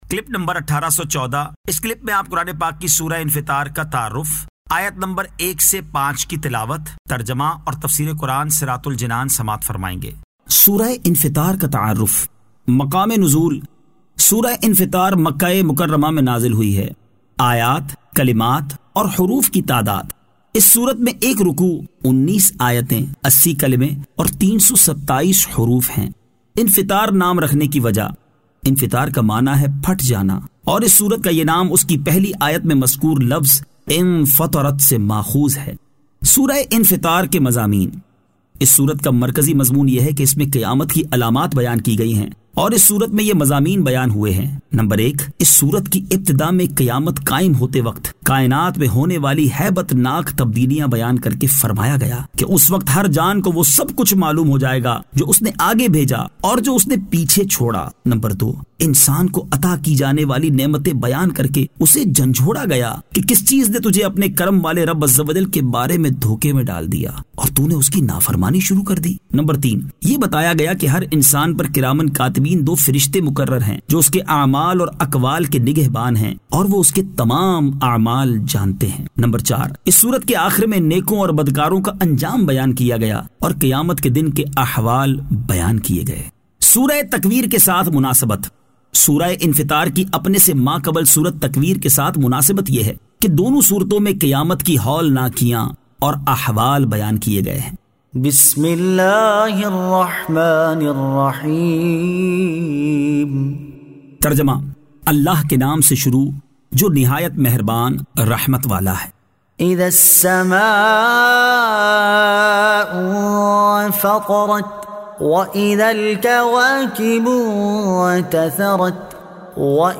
Surah Al-Infitar 01 To 05 Tilawat , Tarjama , Tafseer